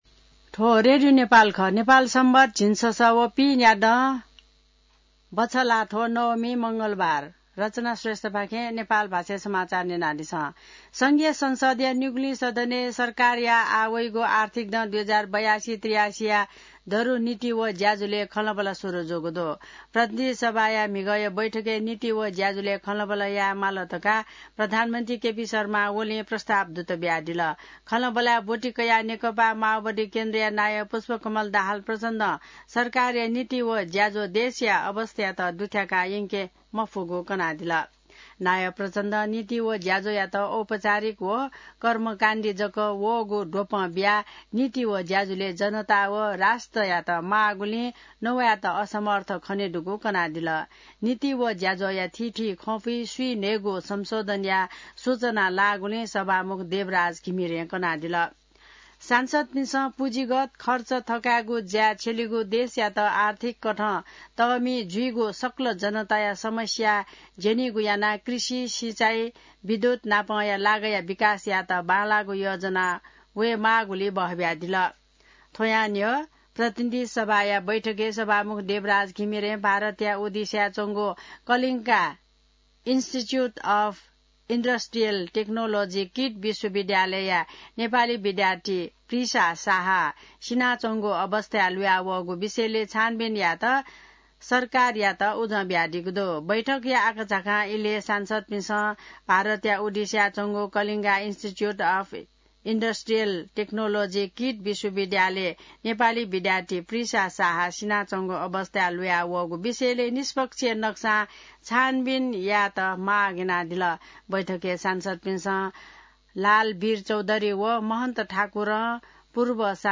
नेपाल भाषामा समाचार : २३ वैशाख , २०८२